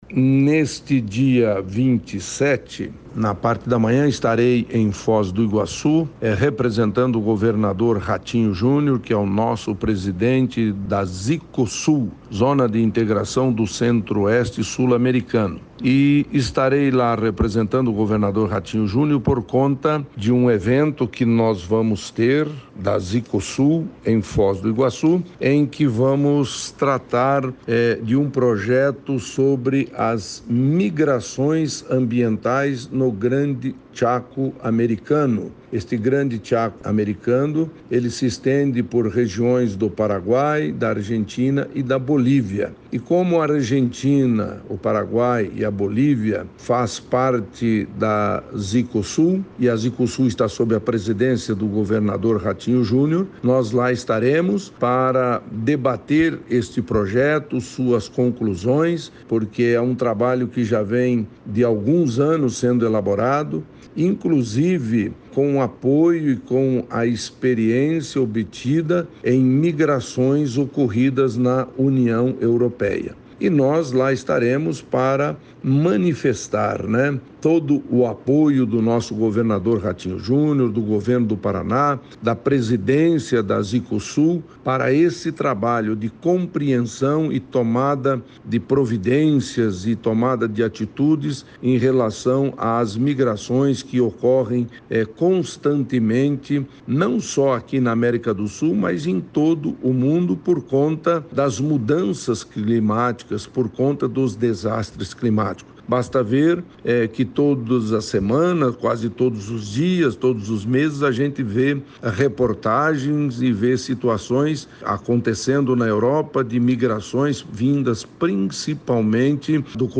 Sonora do secretário do Codesul, Orlando Pessuti, sobre o seminário que discute a influência de mudanças climáticas nas migrações na América do Sul